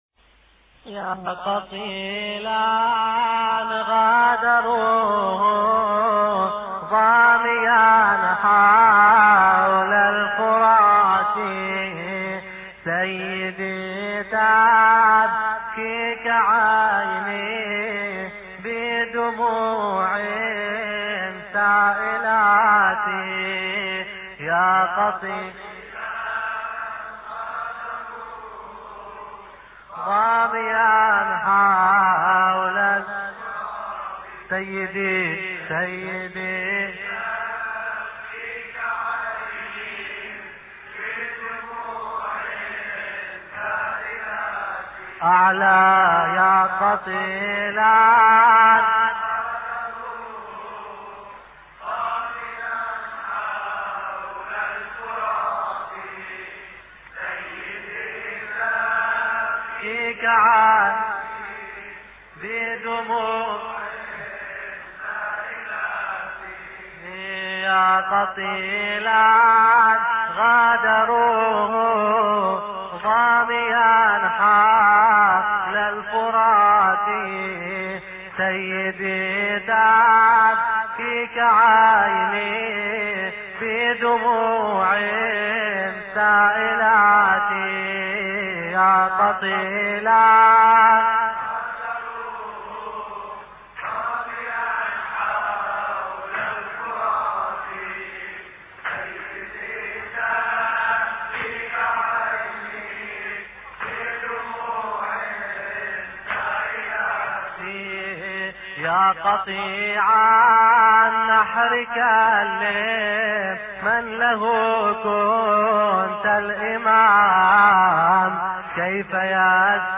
مراثي الامام الحسين (ع)